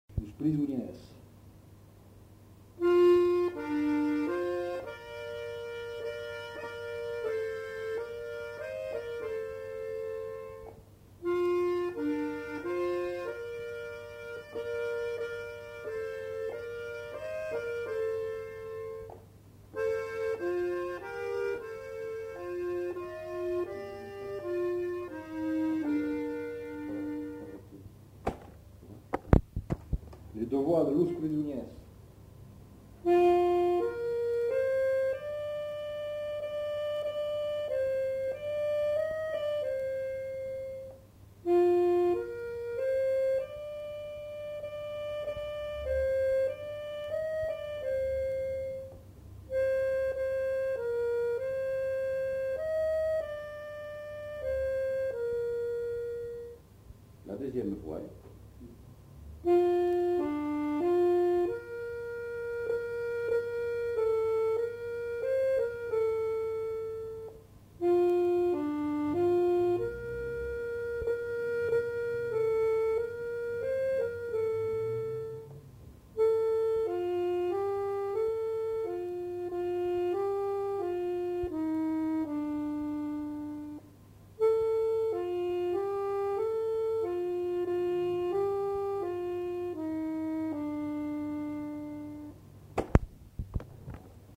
Répertoire d'airs gascons joué à l'accordéon chromatique
enquêtes sonores